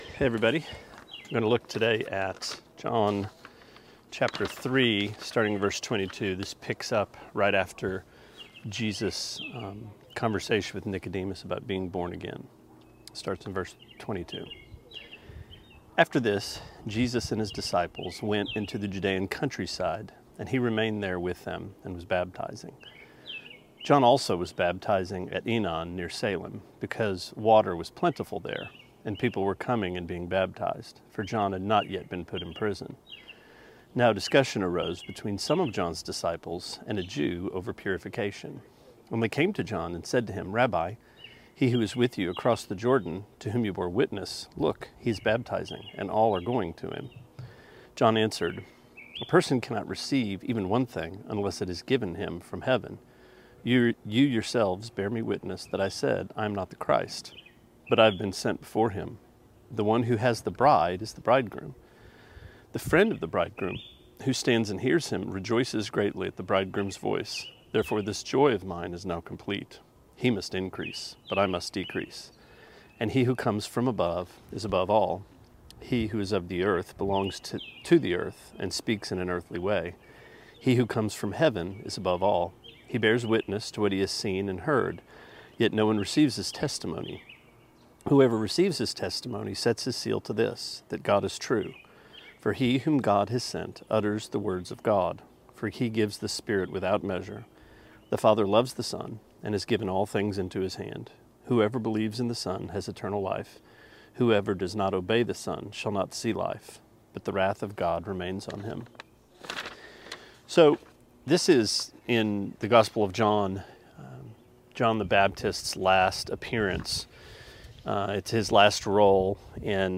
Sermonette 4/22: John 3:22-36: The Friend of the Bridegroom